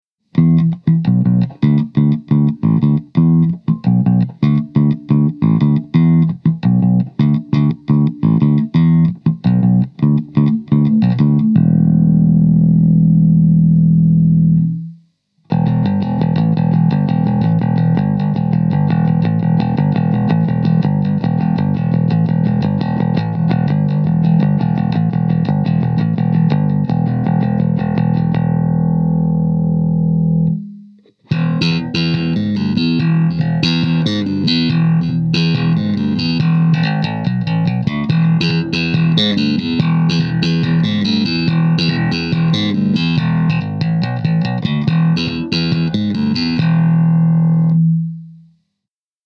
Супер-низких частот от такого маленького динамика (6") вы, естественно, не получите, но звук бас-гитары (и не только) передается отлично.
Примеры звучания комбо через микрофон, в линию и вместе с гитарой прилагаются.
1. Aria AB-20 через микрофон 1,16 Мб
aria_ab-20_mic.mp3